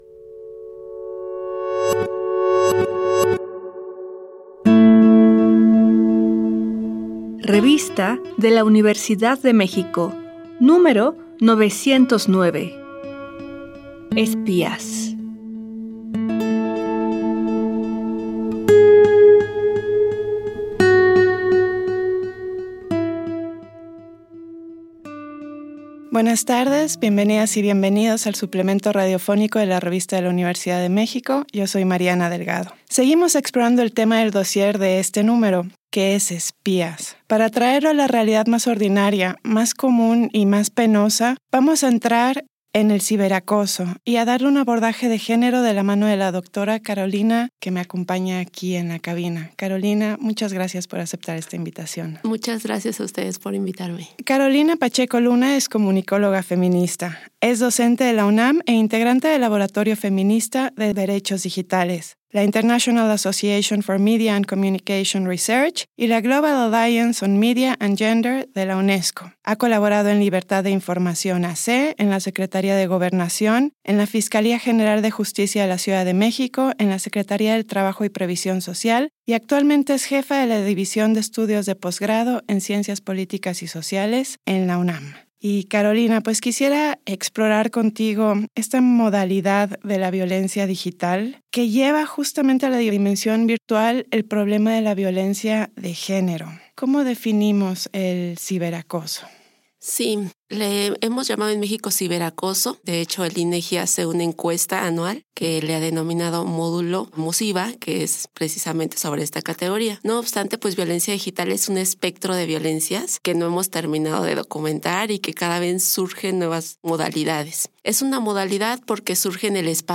Fue transmitido el jueves 13 de junio de 2024 por el 96.1 FM.